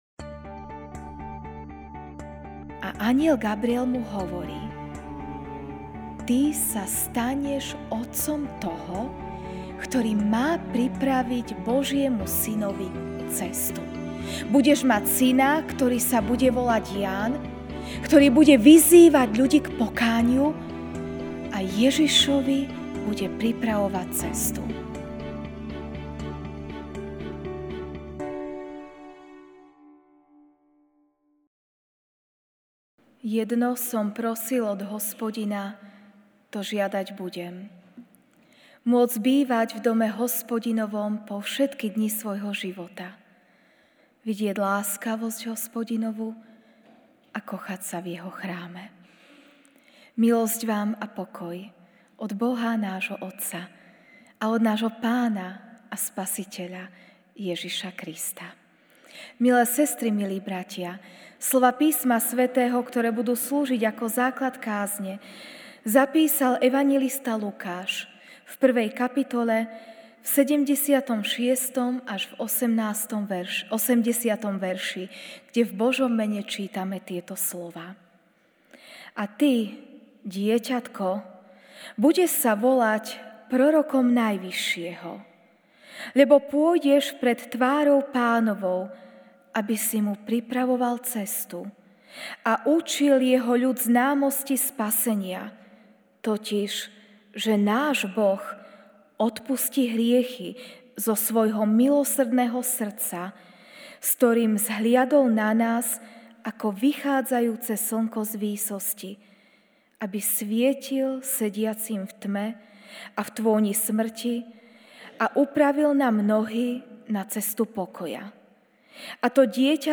Večerná kázeň